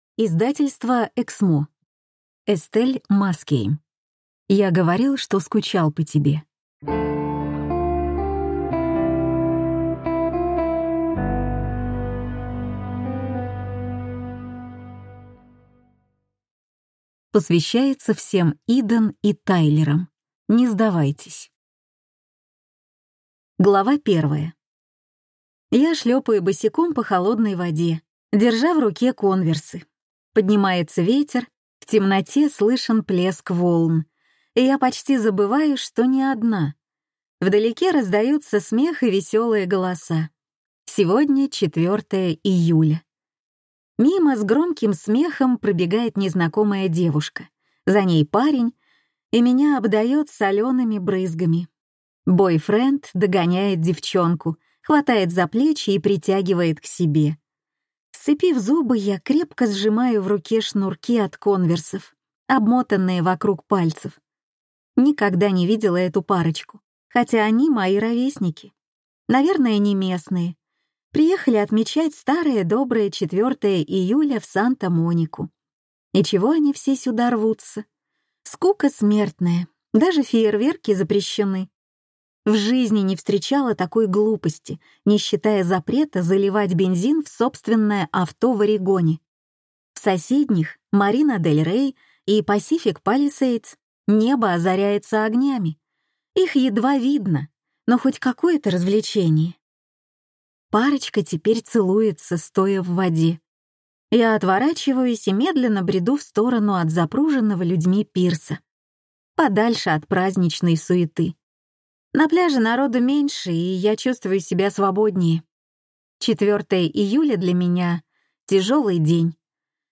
Аудиокнига Я говорил, что скучал по тебе?
Прослушать и бесплатно скачать фрагмент аудиокниги